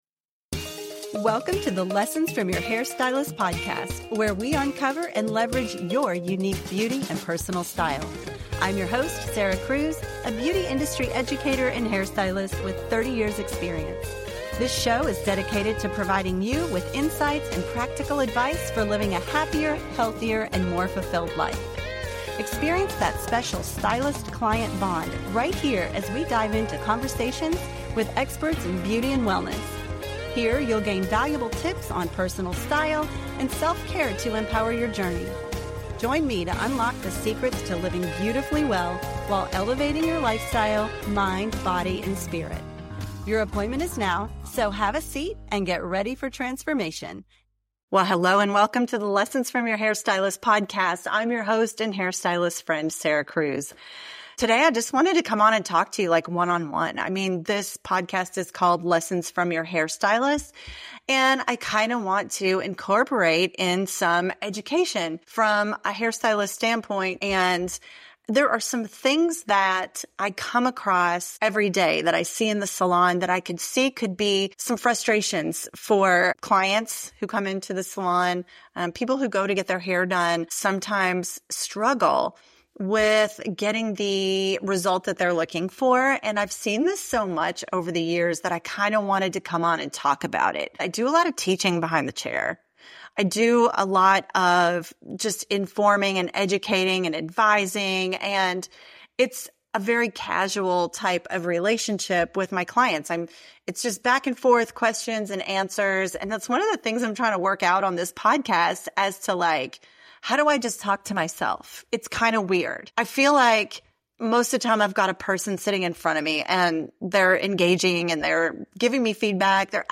In this solo episode